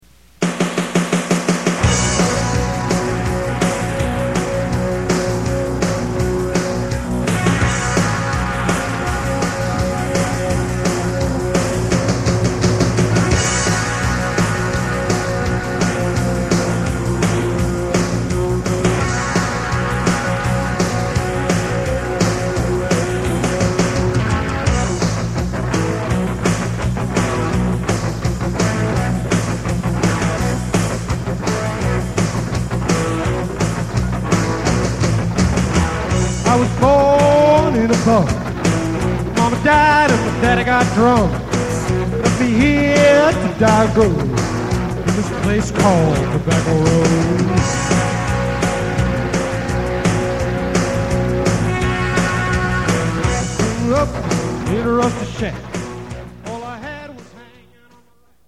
Rock Stuff